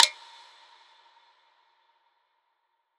PERC - SIX NIGHTS.wav